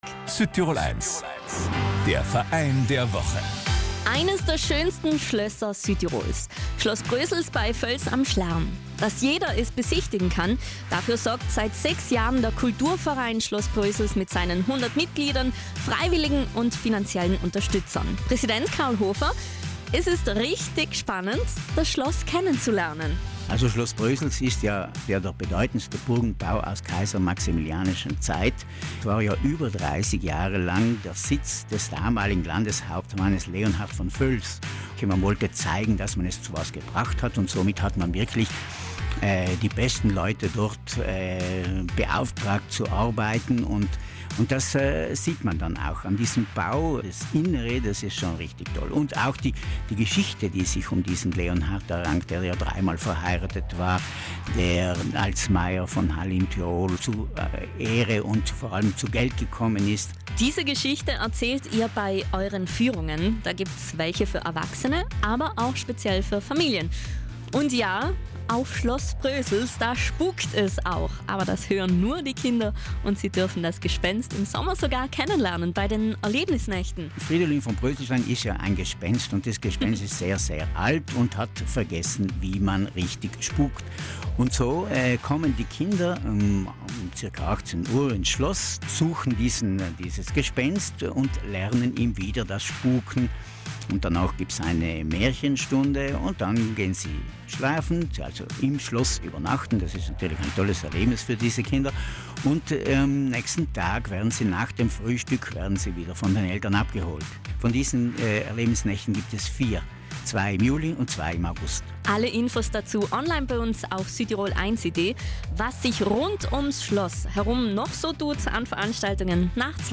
Hier das Gespräch mit unserem Verein der Woche zum Nachhören.